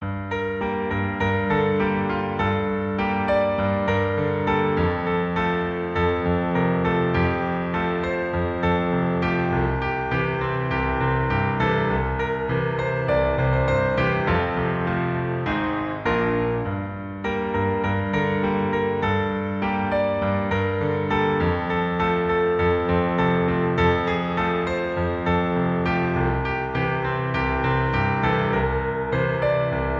• 🎹 Instrument: Piano Solo
• 🎼 Key: G Minor
• 🎶 Genre: Pop, Rock
expressive piano solo arrangement
Arranged in G minor